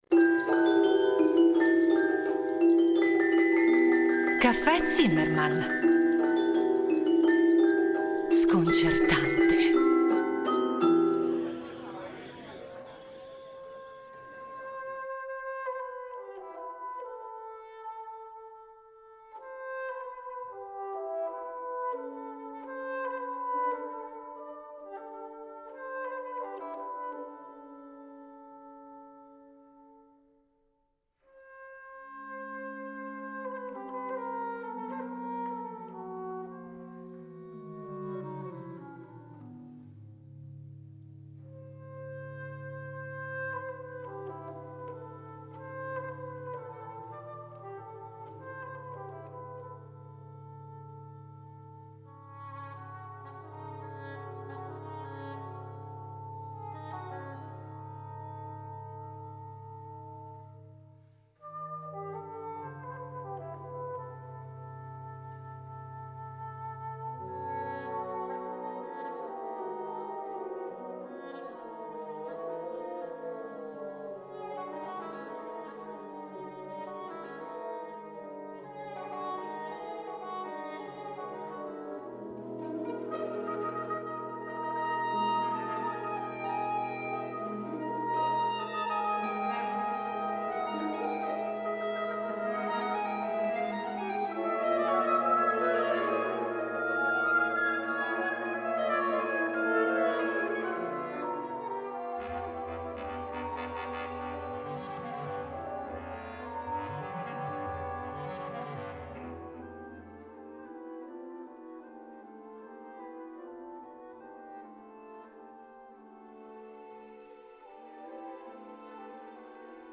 Abbiamo allora deciso di utilizzare, come fil rouge per questo ciclo di cinque puntate, proprio i cinque elementi, verificando la loro presenza, una presenza però che sia peculiare, all’interno di alcune opere del repertorio musicale del Novecento. Da Xenakis a Stravinsky, da De Falla a John Cage passando per Respighi, Scelsi, Nono, sulle tracce di un itinerario sonoro tanto bizzarro quanto ricco di sorprese.